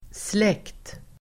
Uttal: [slek:t]